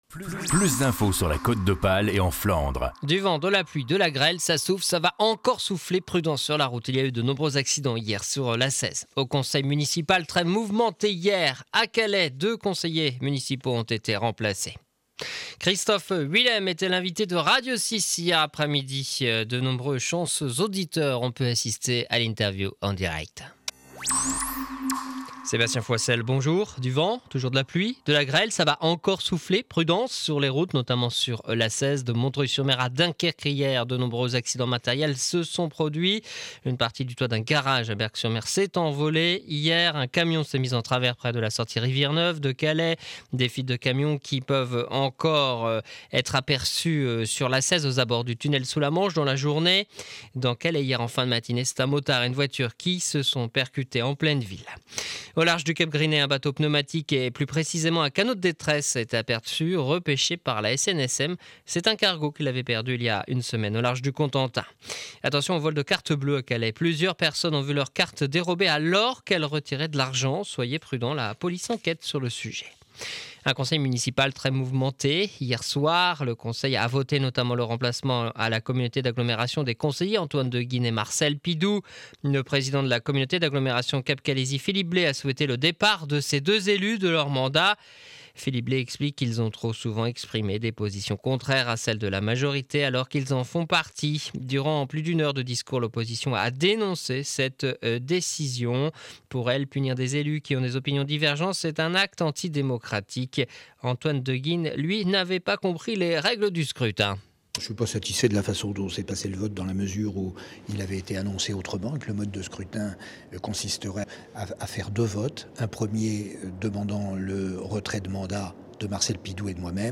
journal du jeudi 15 decembre de 7h30 à Calais